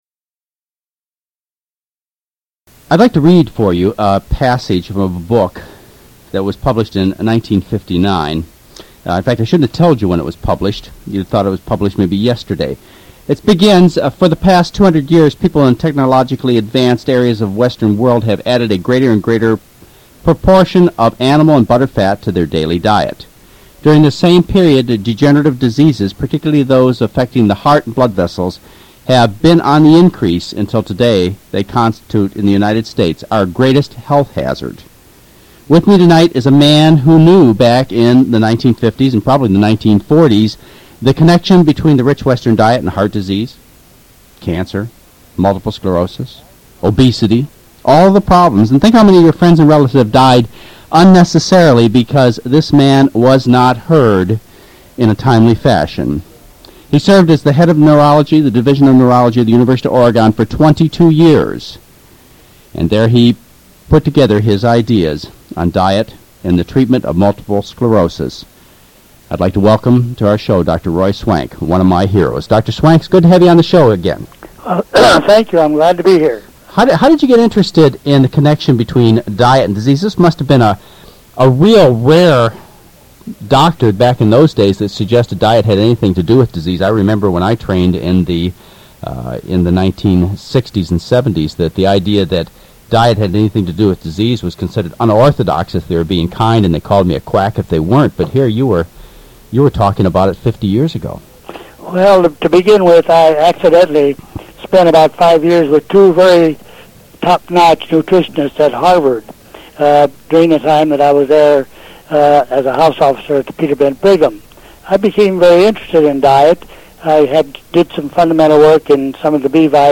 Any contact information provided during the interviews should be confirmed first since it may have changed since the original taping.
Education